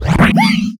CosmicRageSounds / ogg / general / combat / enemy / alianhit1.ogg
alianhit1.ogg